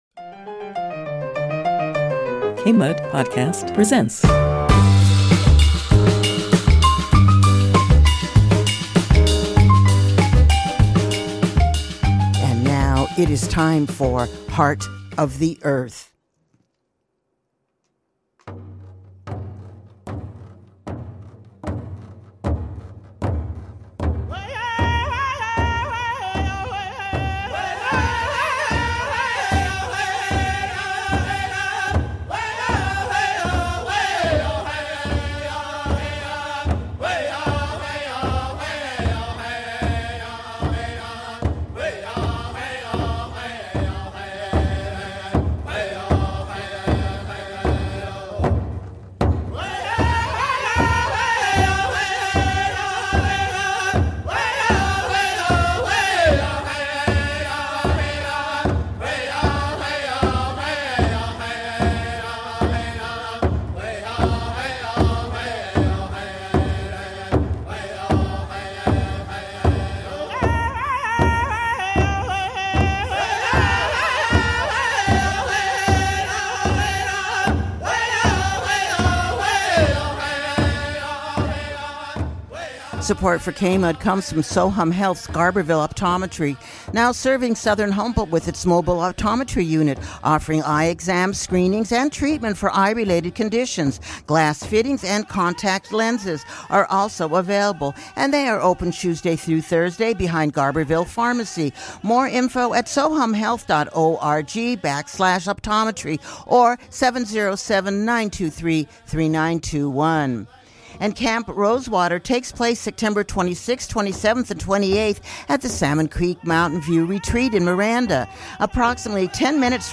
Conversation with Leonard Peltier Pt. 2